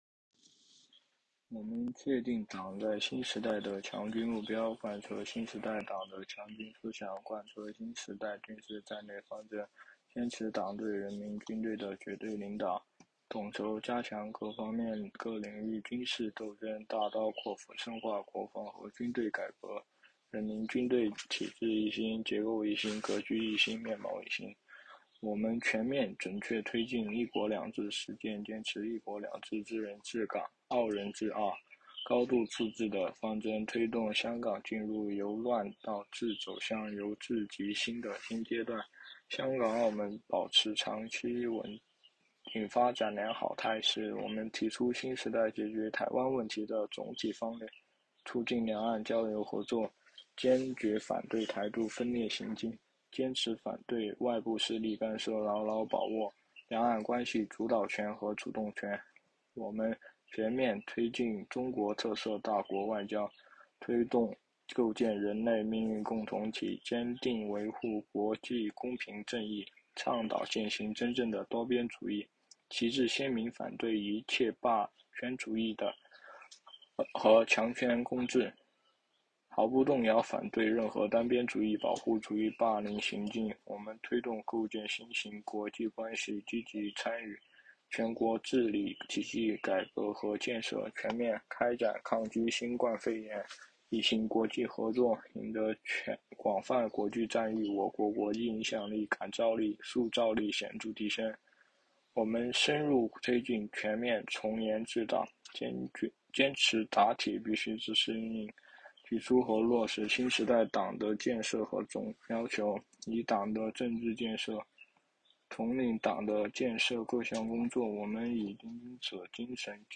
"喜迎二十大 经典咏流传"——西华大学马克思主义经典著作研读会接力诵读（003期）